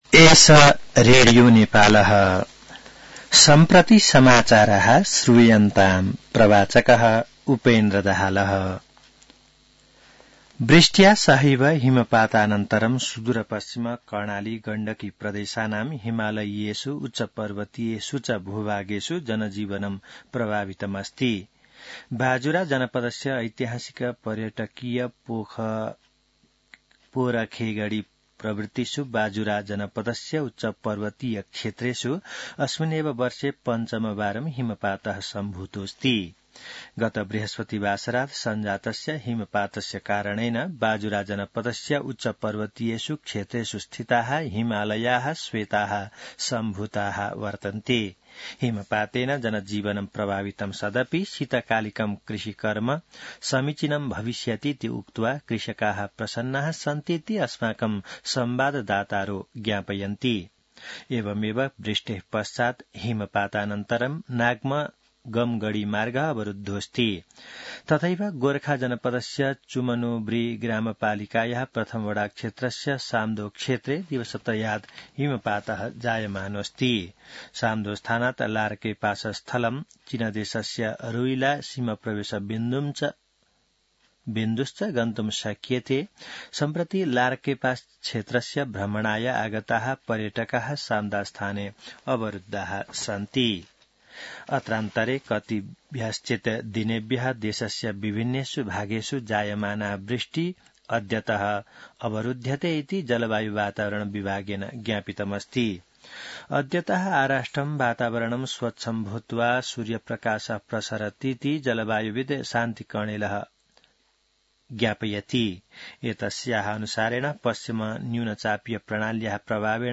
संस्कृत समाचार : १९ फागुन , २०८१